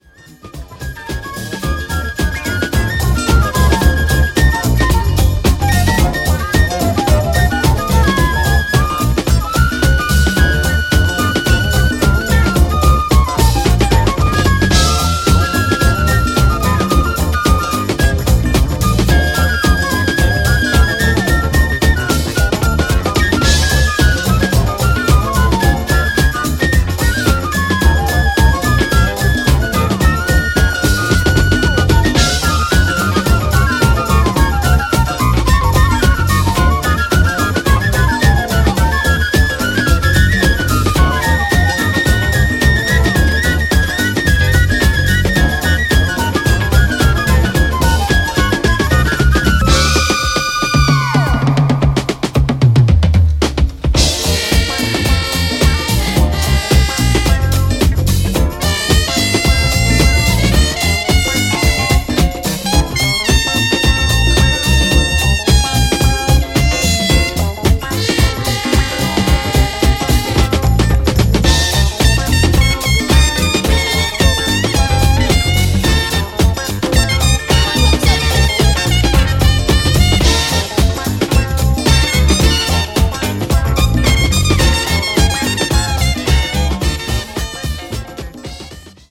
refreshing 1977 jazz-funk tune
with a very thick bottom and a super groovy finish